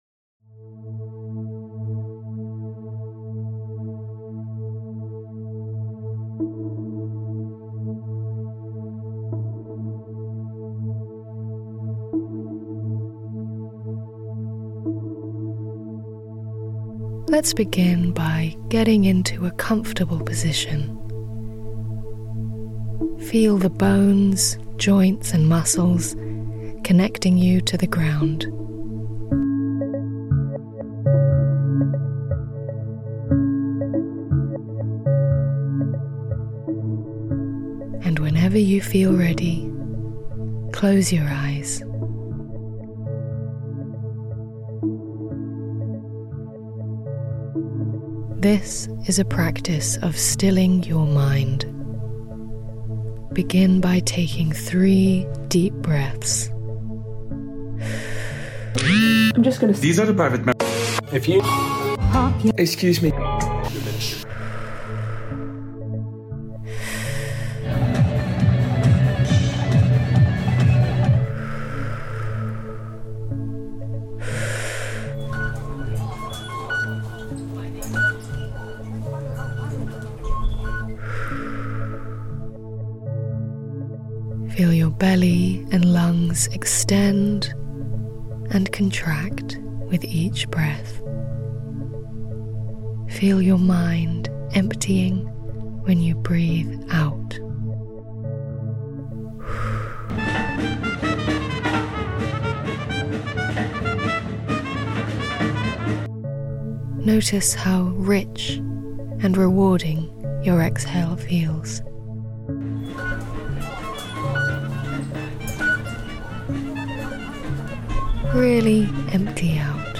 Ferrara busking festival reimagined